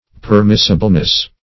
-- Per*mis"si*ble*ness , n. -- Per*mis"si*bly , adv.
permissibleness.mp3